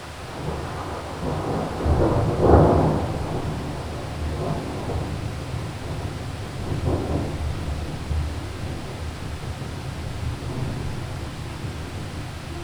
• mountain thunder roll with heavy rain 5.wav
A storm recorded in Southern Carpathian Mountains. Recorded with Tascam DR 40
mountain_thunder_roll_with_heavy_rain_5_aFC.wav